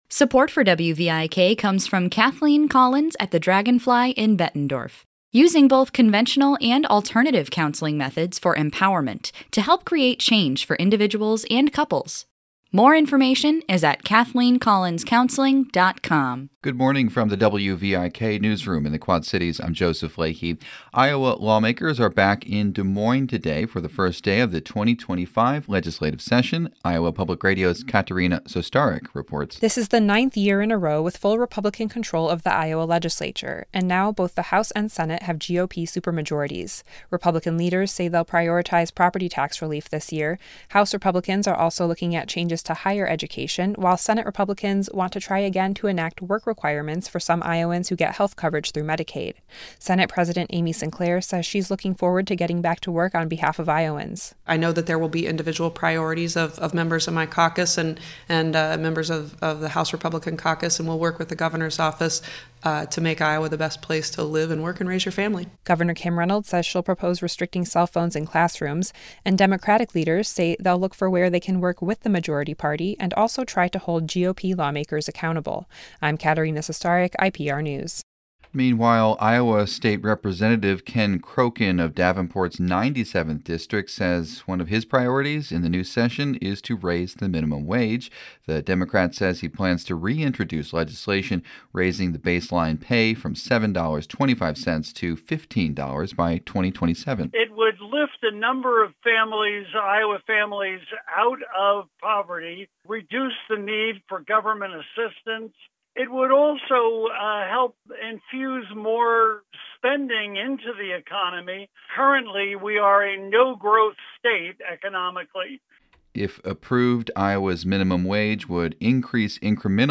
Morning headlines from WVIK News.